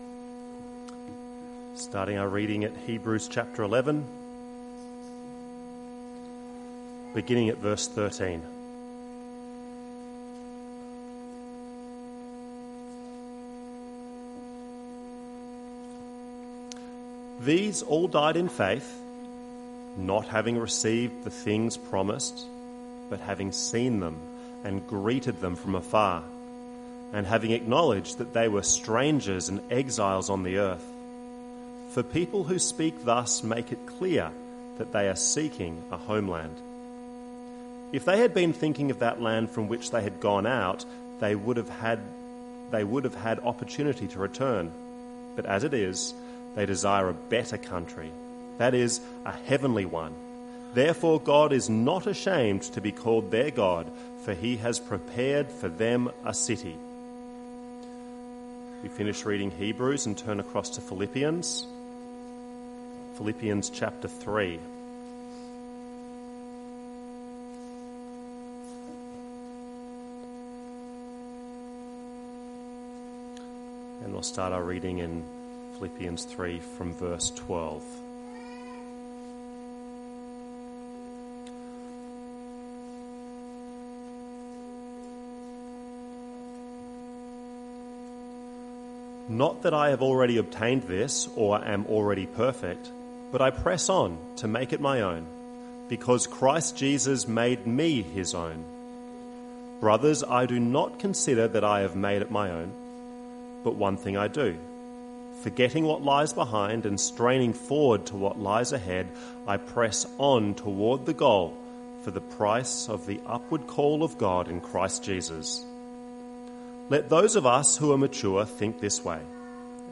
1st of March 2026 - Morning Service